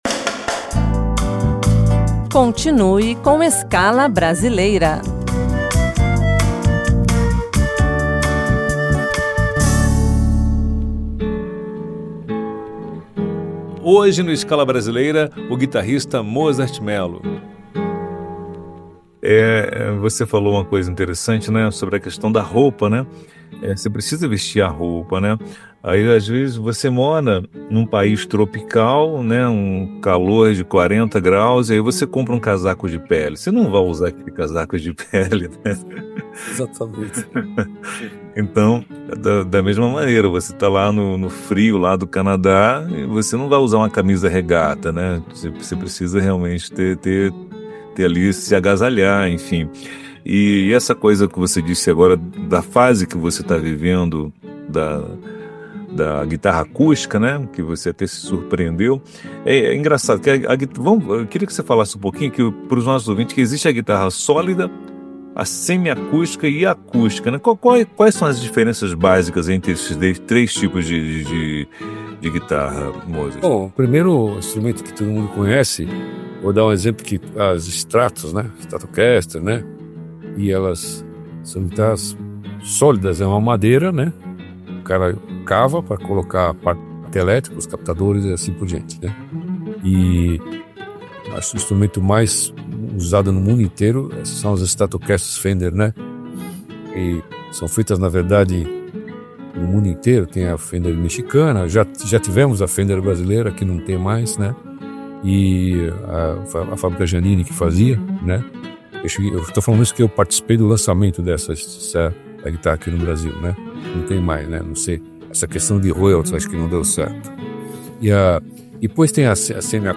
Rádio Senado
2ª parte - A guitarra perfeita